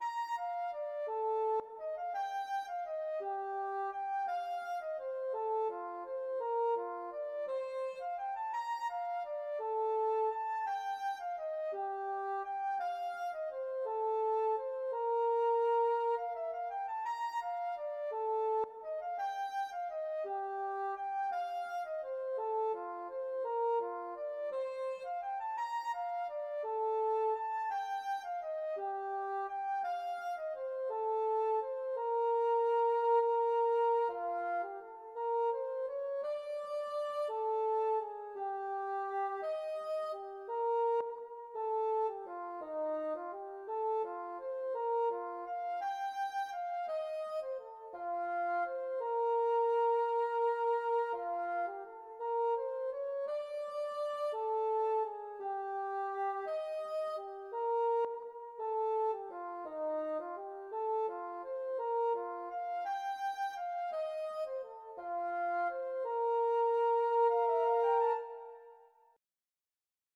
Valse irlandaise